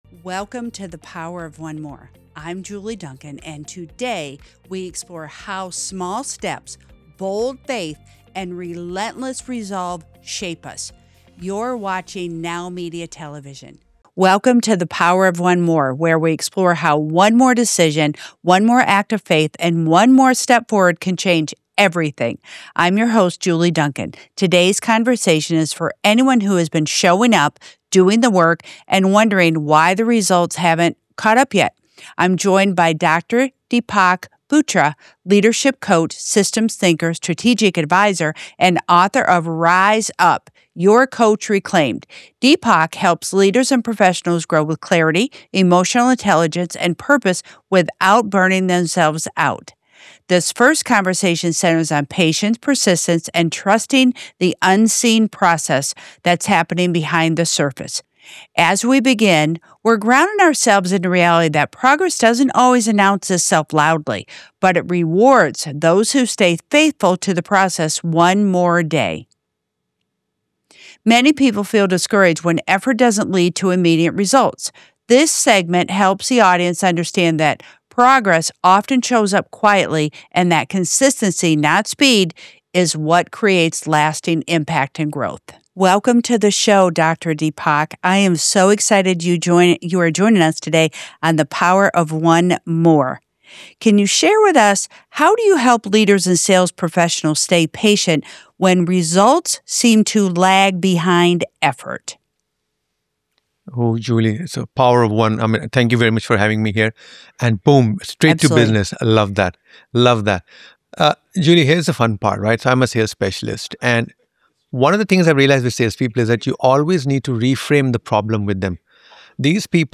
This conversation focuses on patience, persistence, and learning to trust the unseen progress happening beneath the surface.